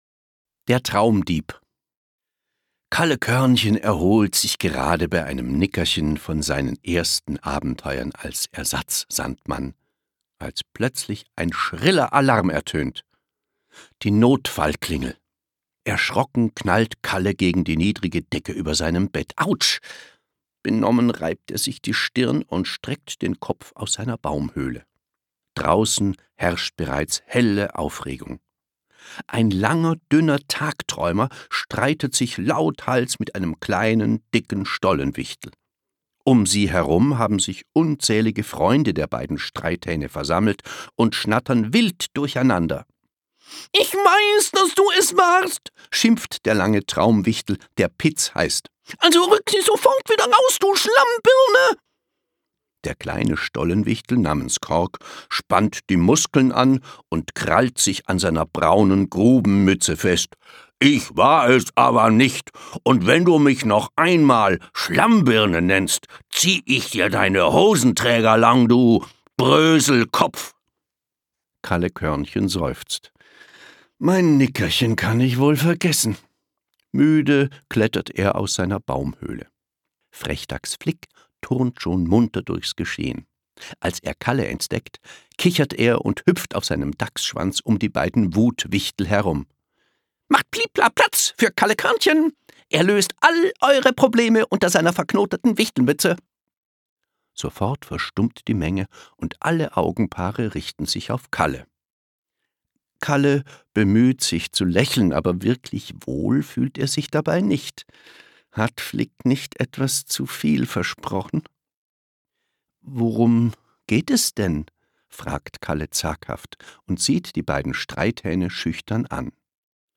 Hörbuch: Kalle Körnchen.